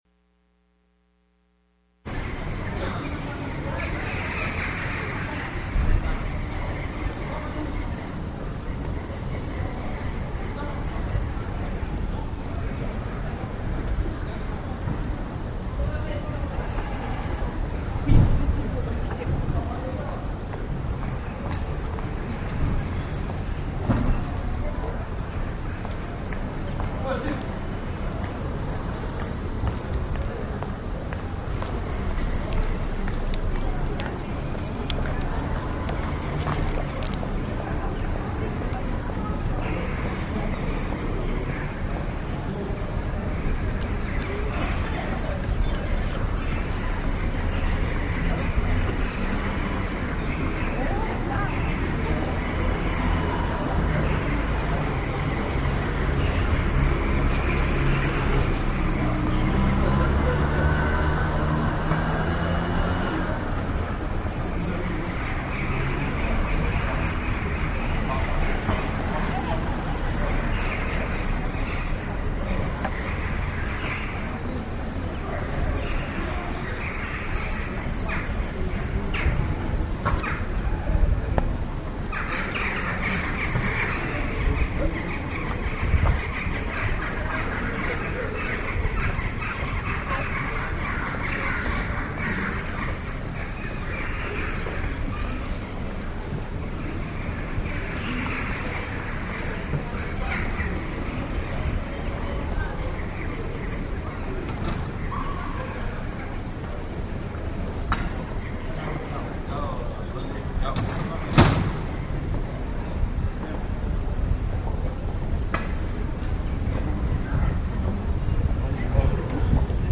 The twelve enumerated locations are the sites at which I am making my binaural field recordings. Recordings are made while facing north so that the perceived location of the bells changes each hour. Although it may be impossible to deduce the location of the clock tower by sound alone, each space enjoys its own flavor of ambience and sound signature.
towerbells.mp3